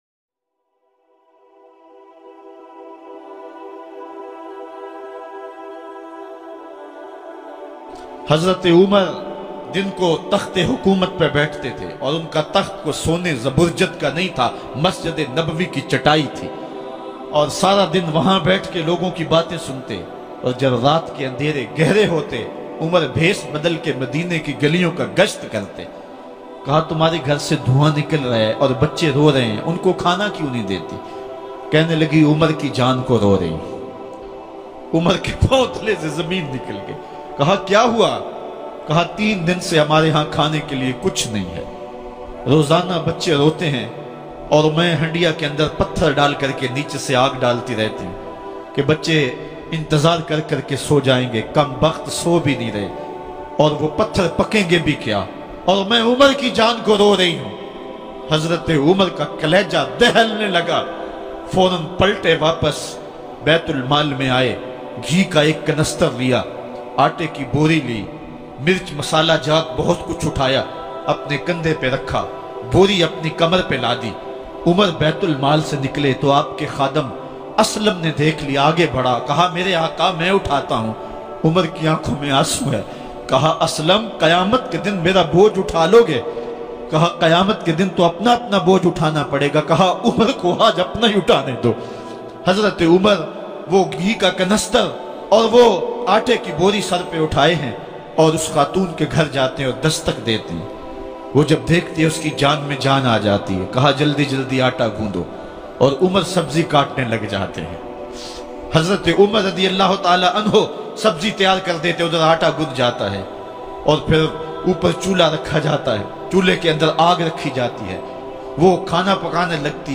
HAZRAT UMAR RA Ki Saadgi - Rula Dene Wala Bayan 2020.mp3